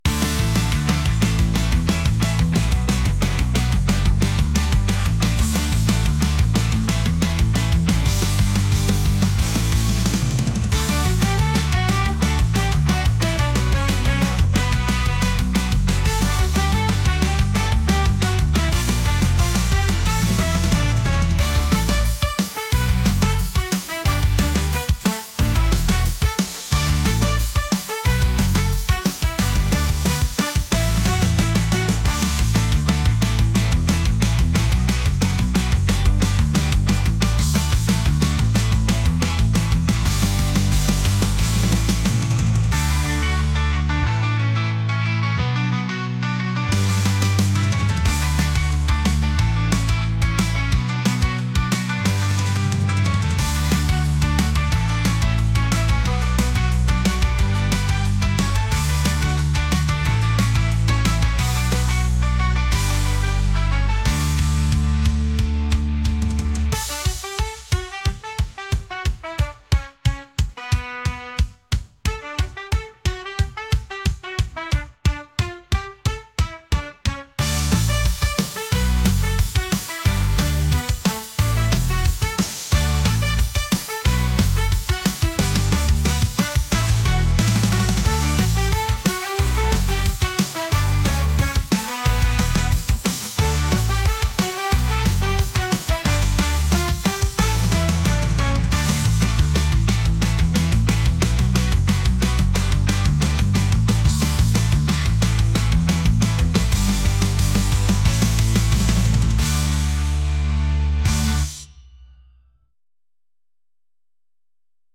energetic | ska | punk